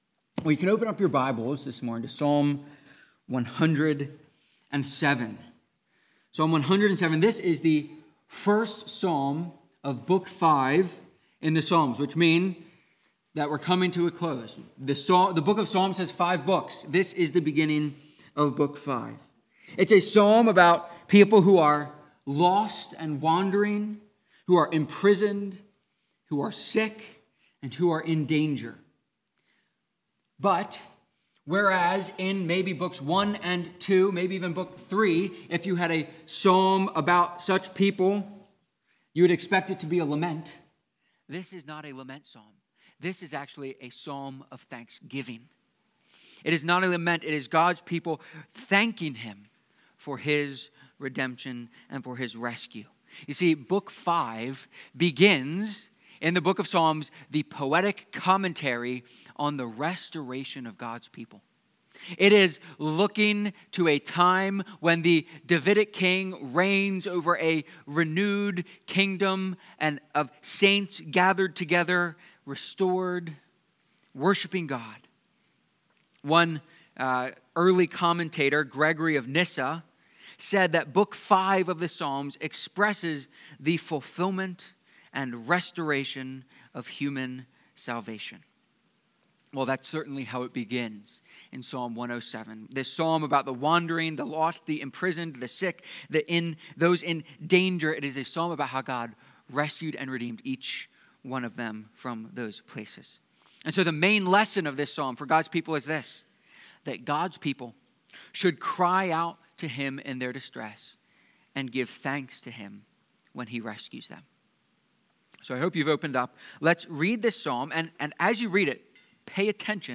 Psalm-107-sermon.mp3